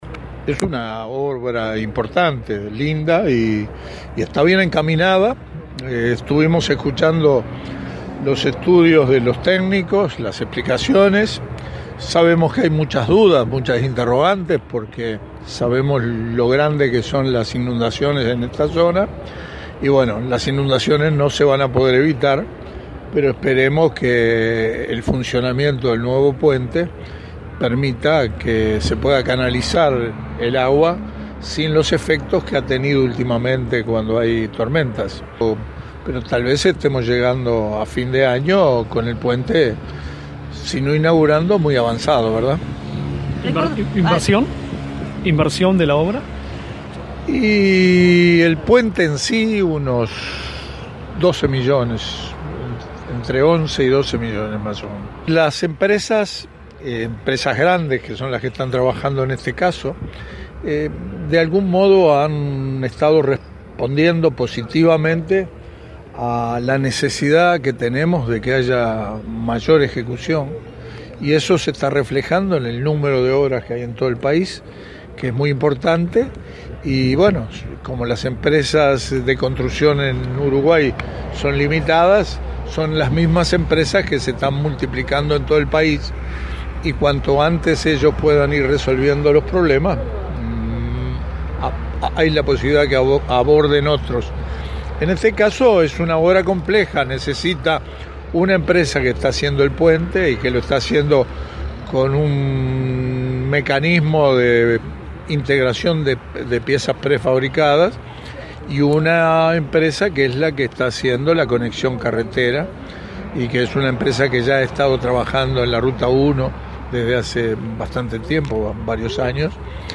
El ministro de Transporte, Víctor Rossi, adelantó que sobre fin de año estará prácticamente terminada la obra del nuevo puente sobre el río Rosario, en Colonia, que demandó una inversión de 12 millones de dólares. Se tomaron una serie de medidas que permiten drenar las aguas y mejorar la problemática por inundaciones en la ruta en esa zona, sostuvo Rossi a la prensa en Colonia.